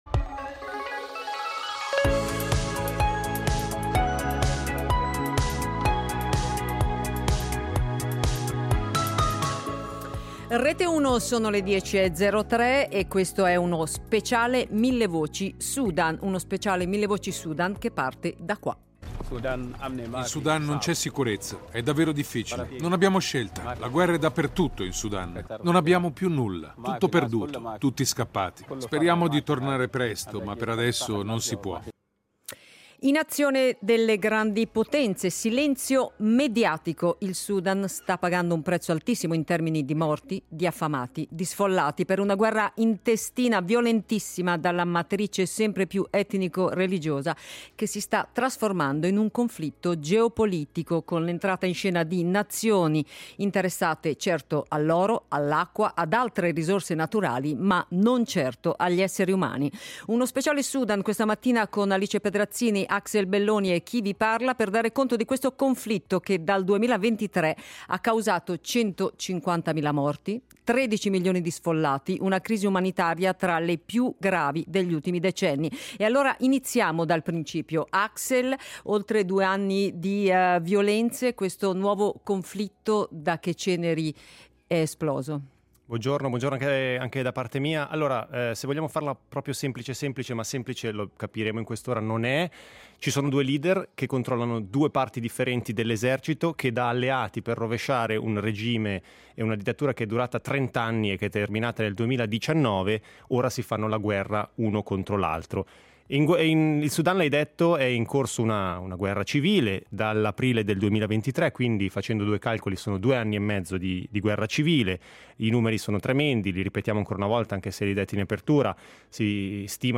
In Sudan si continua a morire di fame e di violenza in un conflitto poco raccontato. Origine evoluzione e situazione umanitaria del Paese Africano, saranno al centro della puntata che grazie a testimonianze dirette ed analisti ci porterà più vicini alla realtà dei fatti: milioni di persone colpite dalla fame dallo sfollamento e dal crollo dei servizi essenziali.